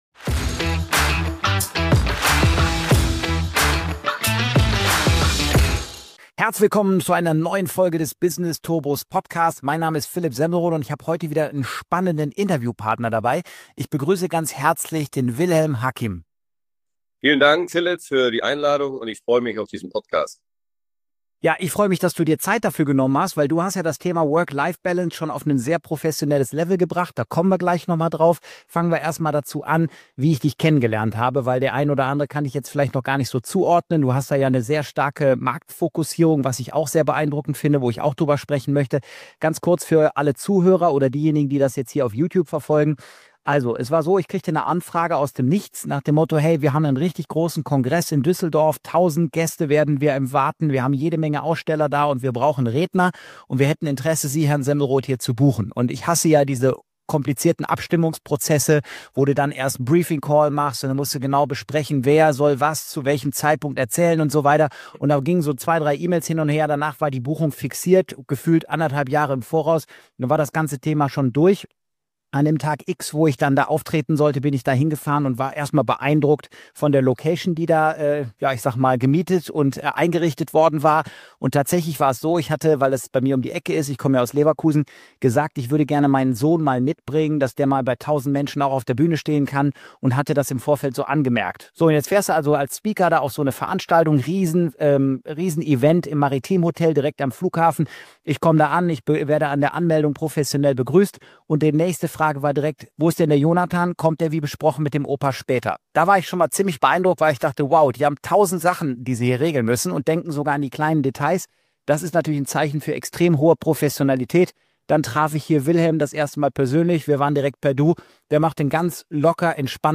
#052 - INTERVIEW